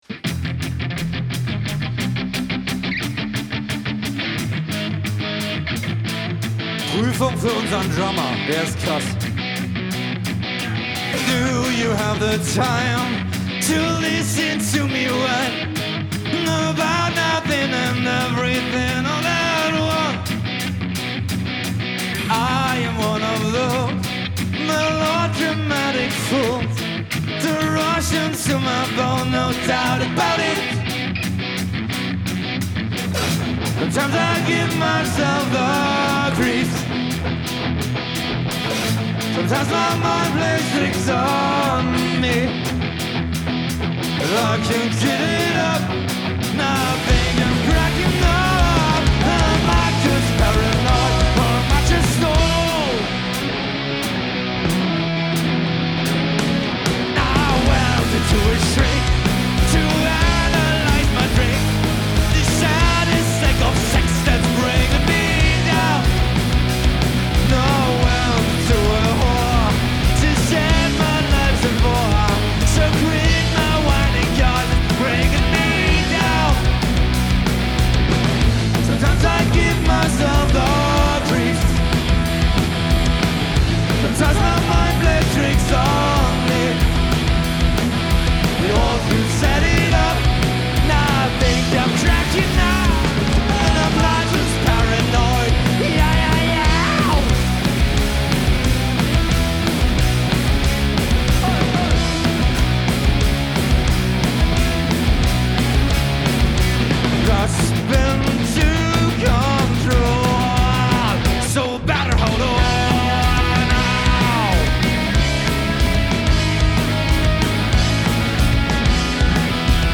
Rhythmus Gitarre
Bass
Drums
Lead-Gitarre
Live